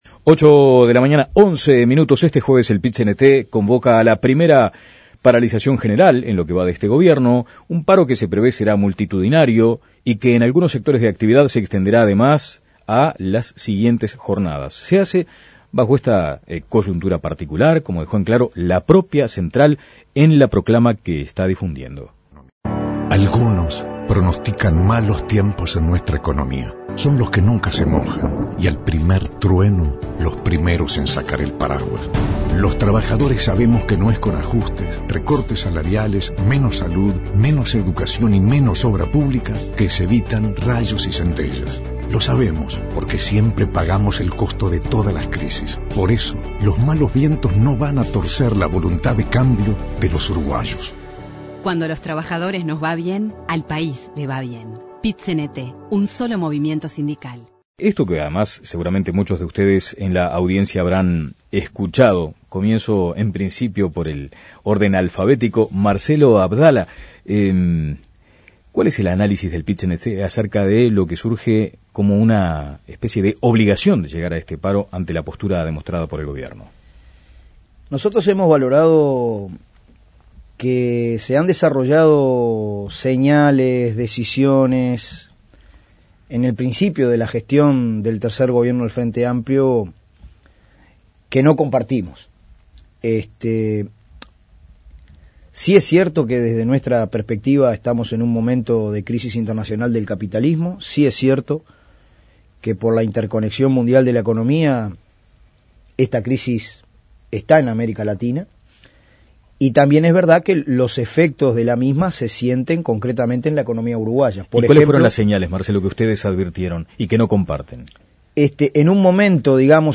Fernando Pereira y Marcelo Abdala, presidente y Secretario general del Pit-Cnt respectivamente, conversaron en La Mañana sobre el paro general del próximo jueves 6 de agosto, en el marco de lo consideran el semestre más conflictivo de los últimos 20 años.